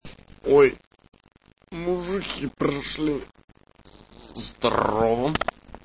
Звуки мужского голоса